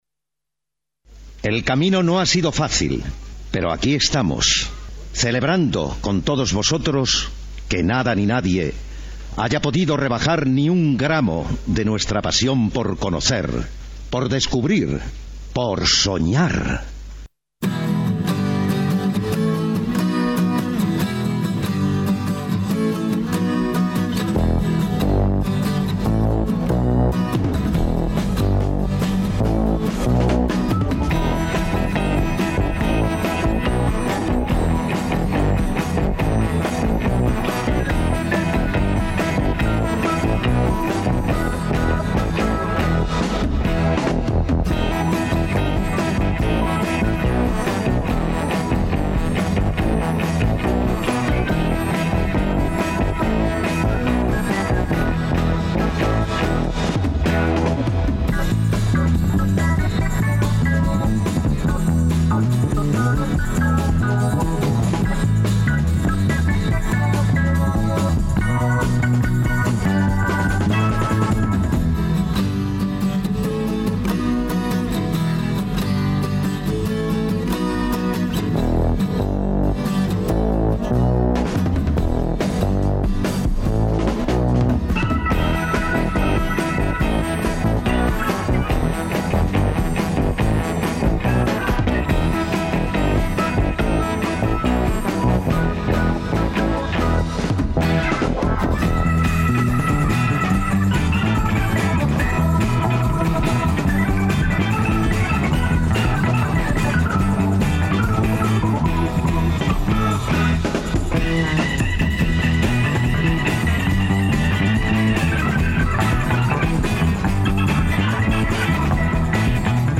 Honetarako, bidean ahaztutako abesti bitxiak berreskuratzen ditugu.
Gaurkoan, estilo ezberdinak uztartuz sesio heterogeneoa eskaintzen dizuegu.